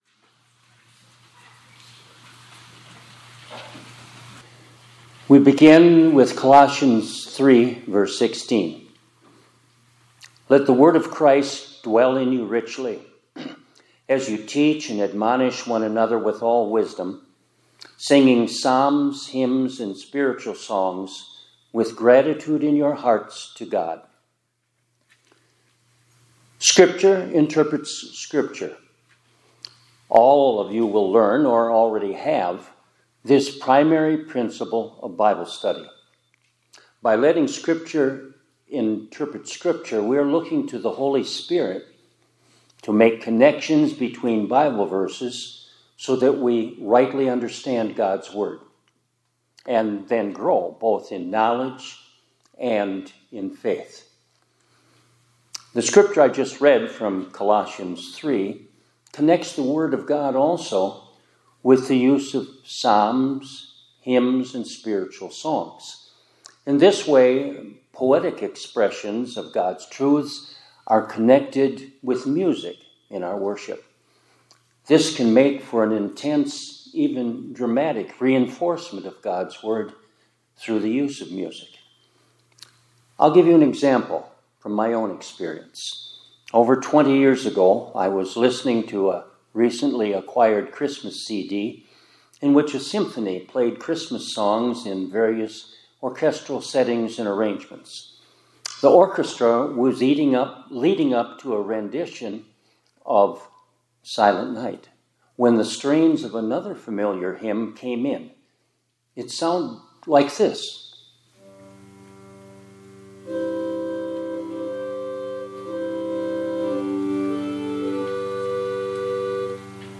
2025-03-11 ILC Chapel — Let the Word of Christ Dwell in You Richly